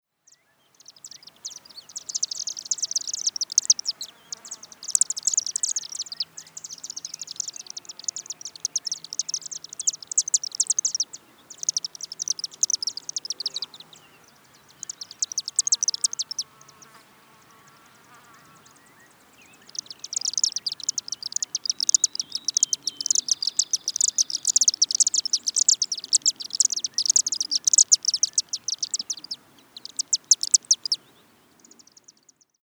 На этой странице собраны звуки, издаваемые стрижами: их звонкое щебетание, крики в полёте и другие природные голоса.
Дымчатый иглохвост издает необычный звук при общении в дикой природе